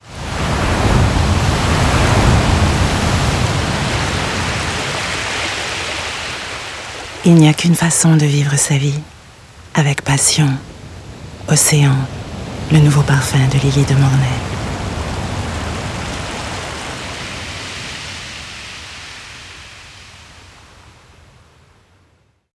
Ana dil spikerleri